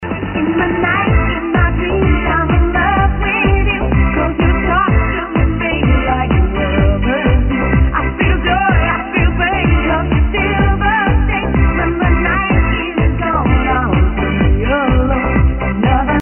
~All tracks are Remixes, unless otherwise stated~